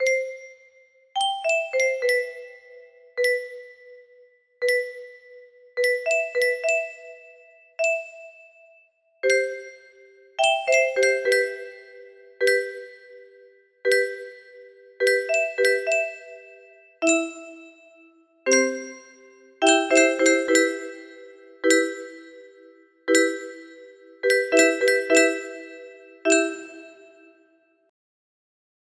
Unknown music box melody